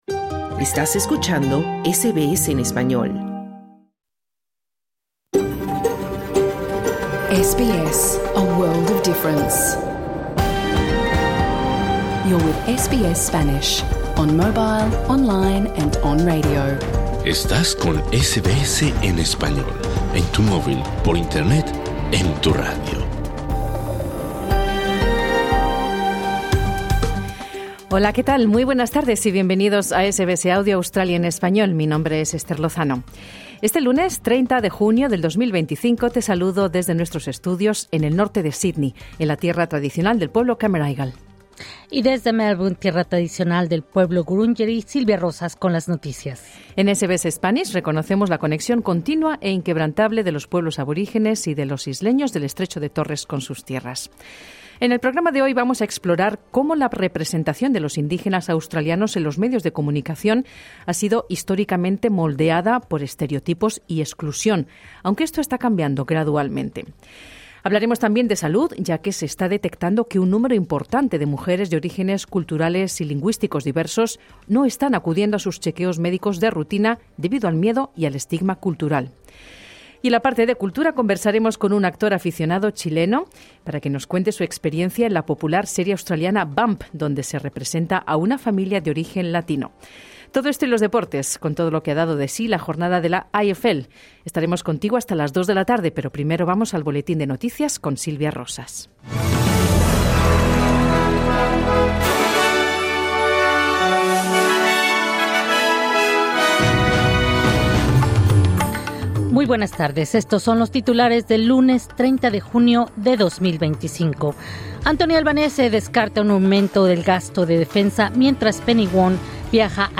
Exploramos si la representación de los indígenas australianos en los medios de comunicación corresponde a la realidad. Hablamos de salud y de porqué un número importante de mujeres de orígenes culturales y lingüísticos diversos no están acudiendo a sus chequeos médicos de rutina. Además, conversamos con un actor aficionado chileno sobre su experiencia en la popular serie australiana “Bump”, donde se representa a una familia de origen latino.